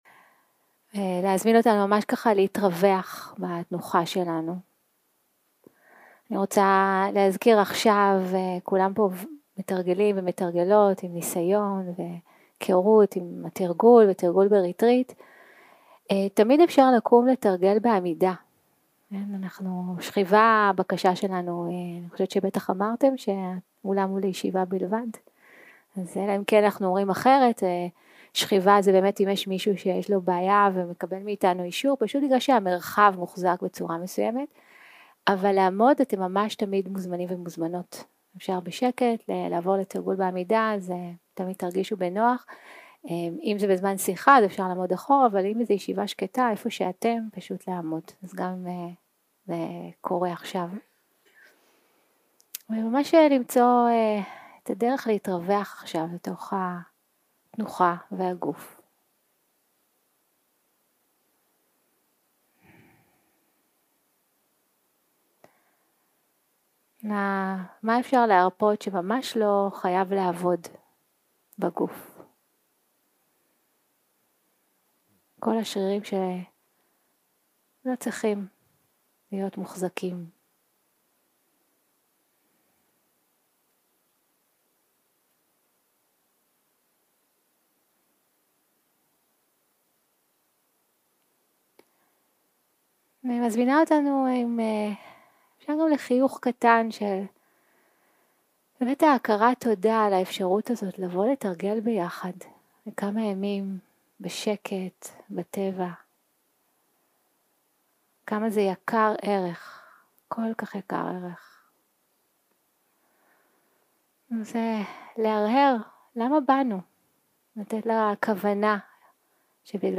סוג ההקלטה: שיחת פתיחה שפת ההקלטה